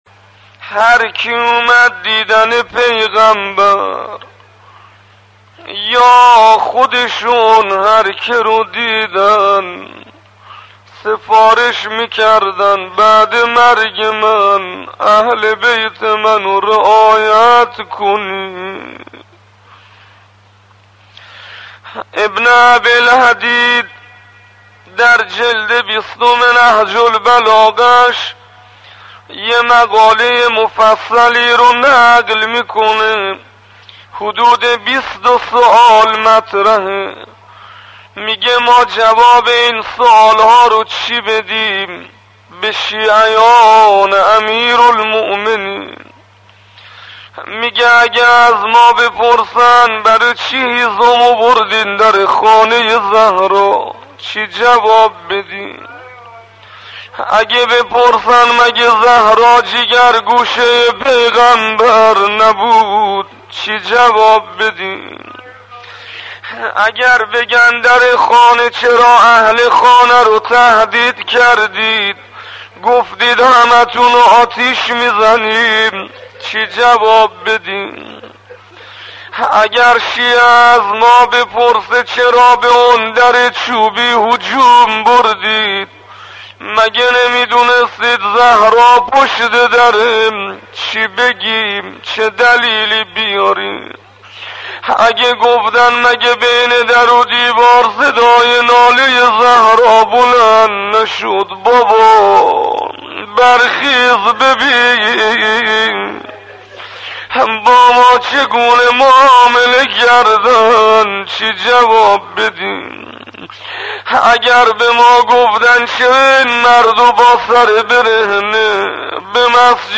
روضه خوانی توسط استاد حسین انصاریان به مناسبت ایام فاطمیه (2:50)